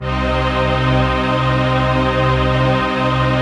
CHRDPAD086-LR.wav